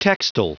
Prononciation du mot textile en anglais (fichier audio)
Prononciation du mot : textile